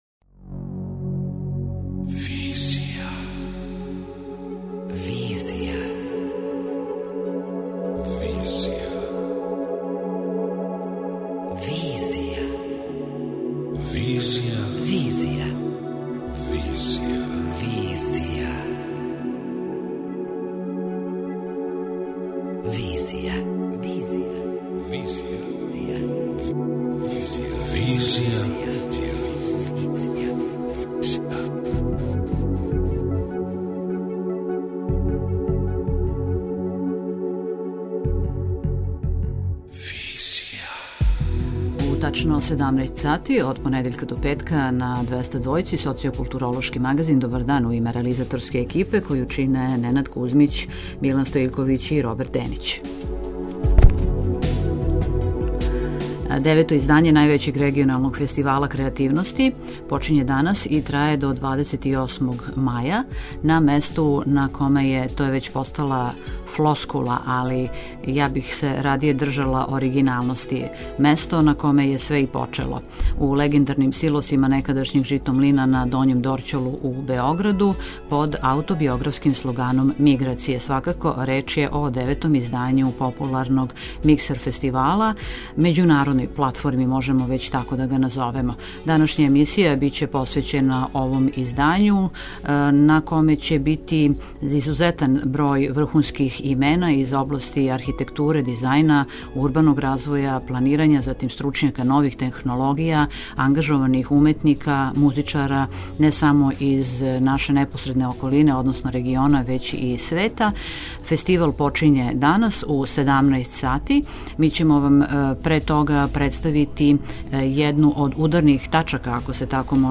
преузми : 27.53 MB Визија Autor: Београд 202 Социо-културолошки магазин, који прати савремене друштвене феномене.